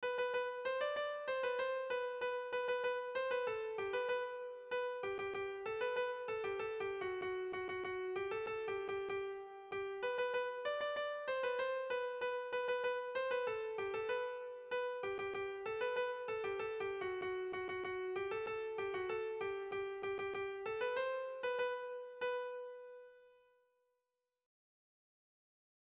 Tragikoa
Zortziko handia (hg) / Lau puntuko handia (ip)